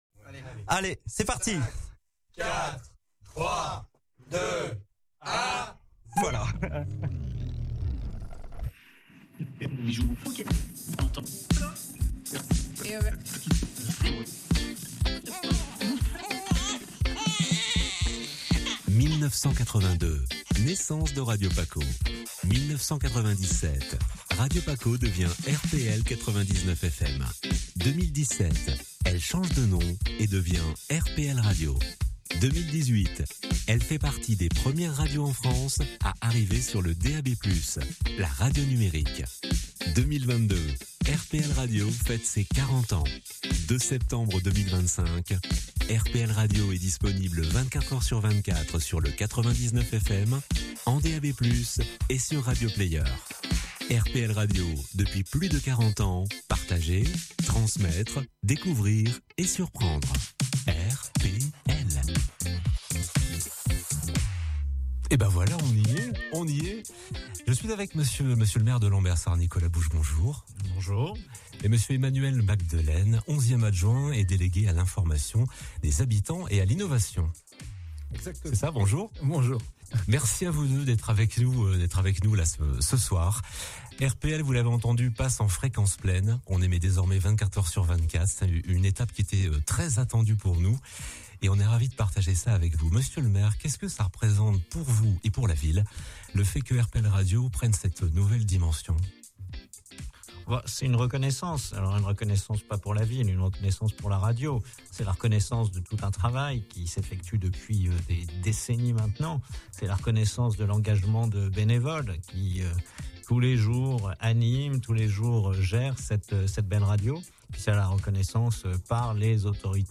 3. INTERVIEWS
Mardi 2 Septembre 2025 - Lancement officiel de RPL Radio en 24h/24 sur le 99.0 fm